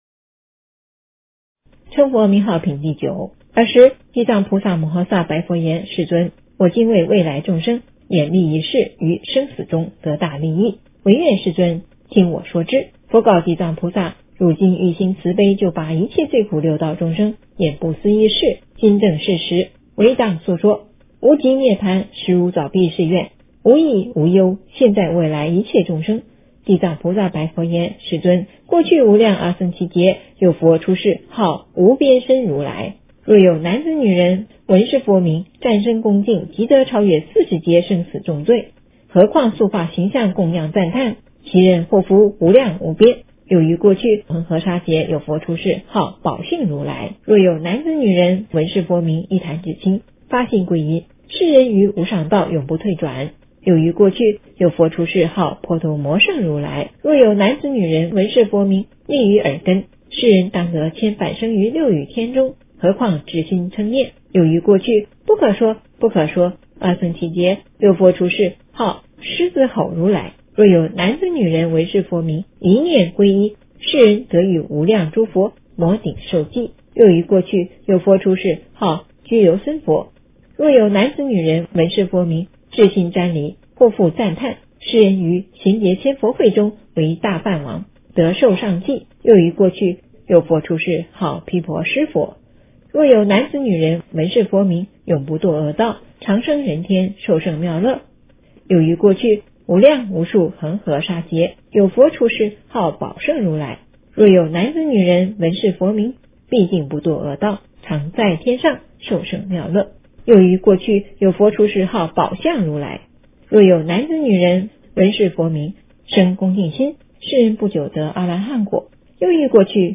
诵经
佛音 诵经 佛教音乐 返回列表 上一篇： 地藏经-阎罗王众赞叹品第八 下一篇： 地藏经-地神护法品第十一 相关文章 伏尔加船夫曲--未知 伏尔加船夫曲--未知...